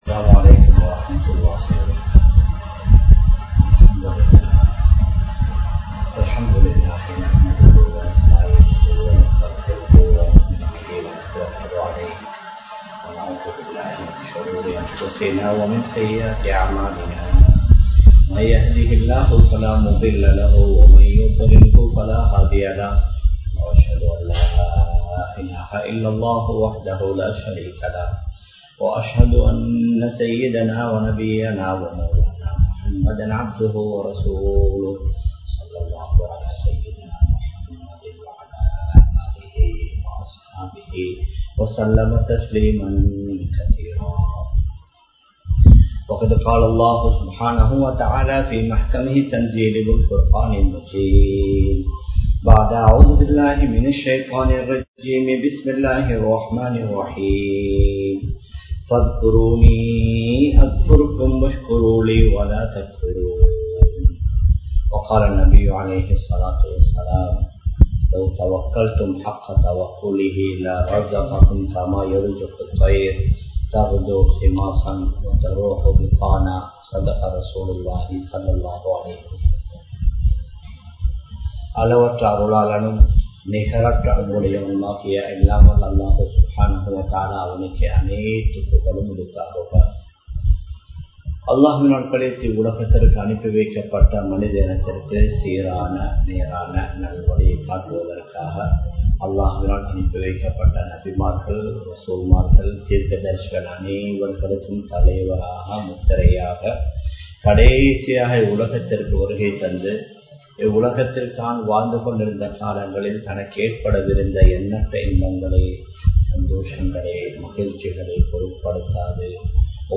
Allahvidam uthavi Theadungal (அல்லாஹ்விடம் உதவி தேடுங்கள்) | Audio Bayans | All Ceylon Muslim Youth Community | Addalaichenai
Live Stream